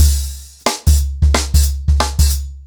TrackBack-90BPM.13.wav